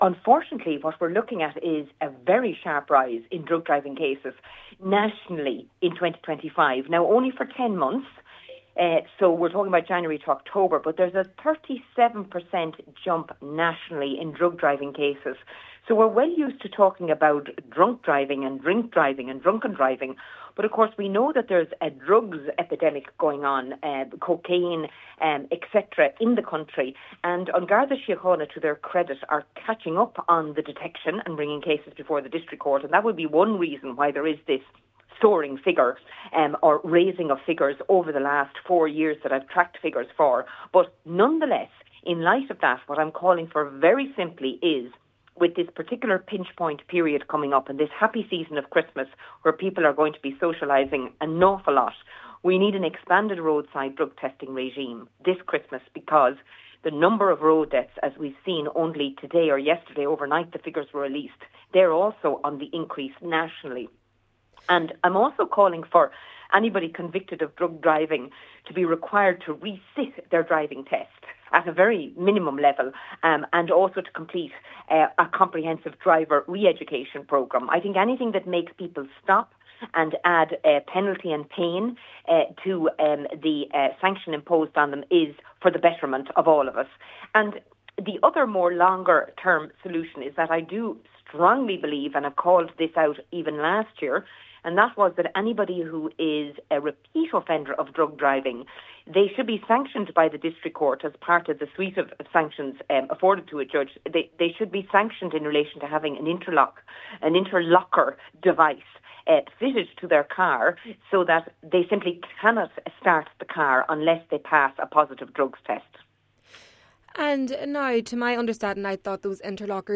The Ireland South MEP says she believes there should be more robust consequences for those found to be driving under the influence: